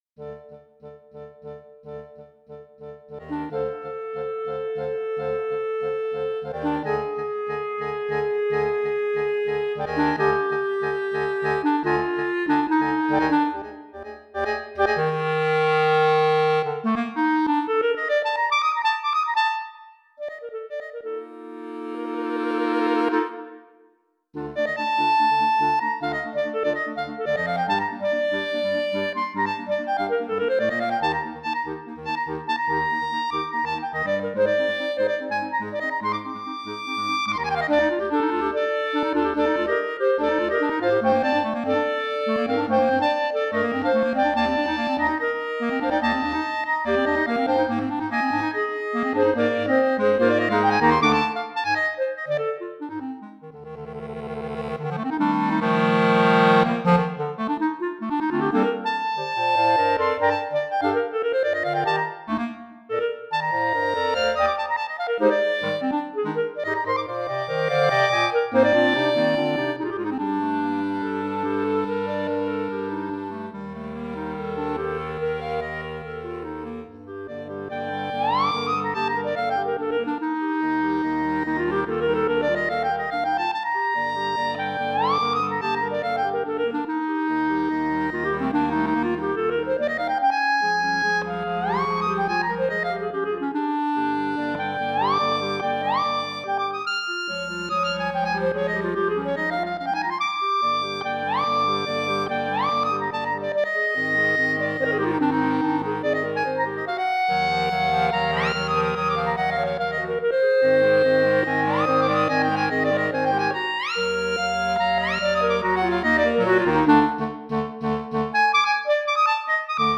Genre: Solo Clarinet + Clarinet Quartet
Solo B-flat Clarinet
B-flat Bass Clarinet